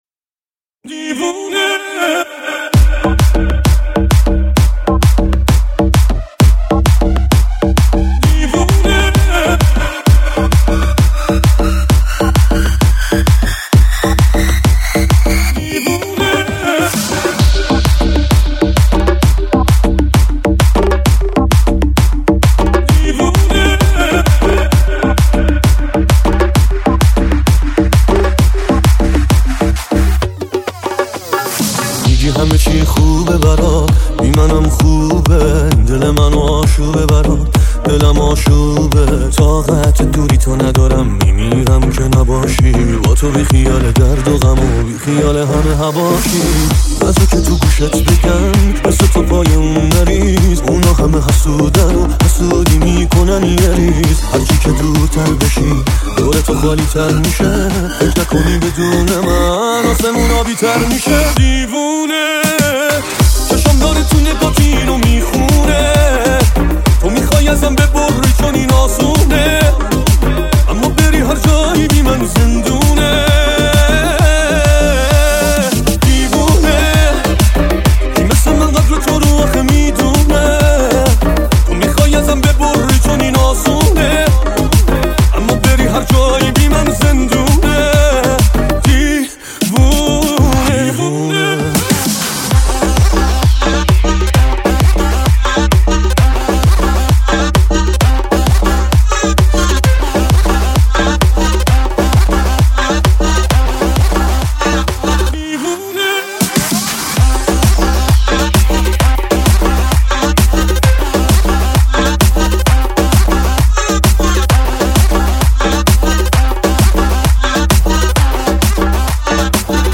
رمیکس
Remix